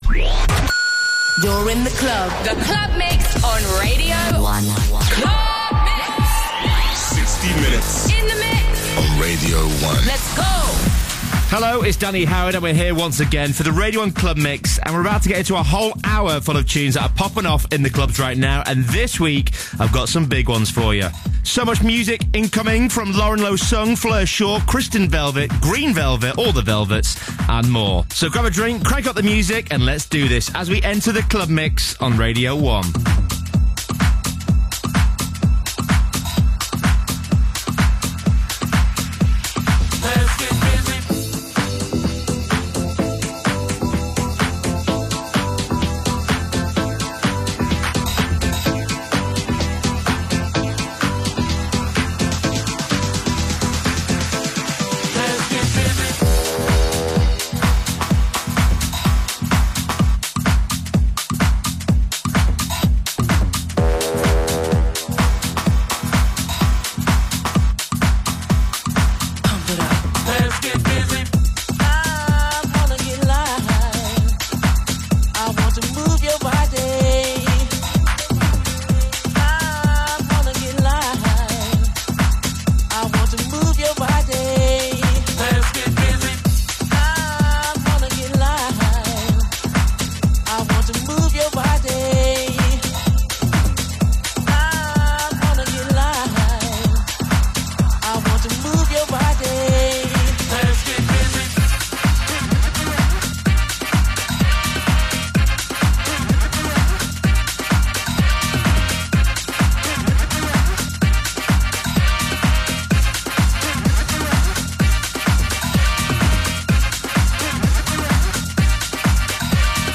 Also find other EDM Livesets, DJ Mixes and Radio